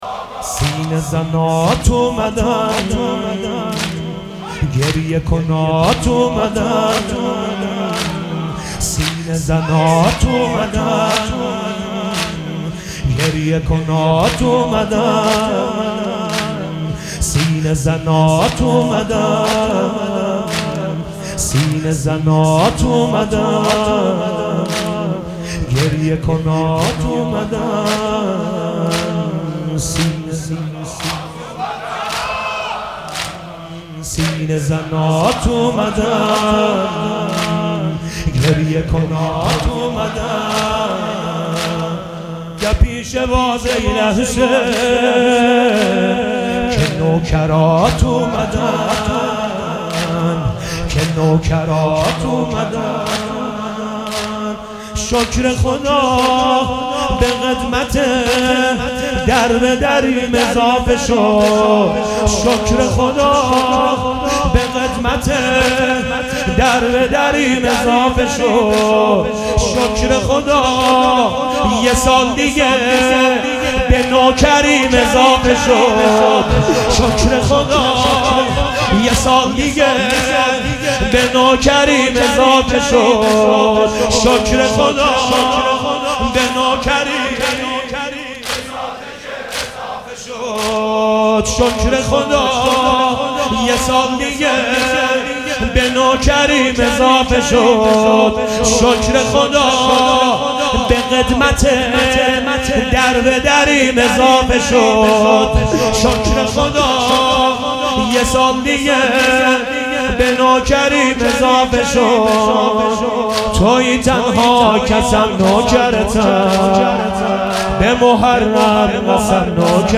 مداحی شب اول محرم 99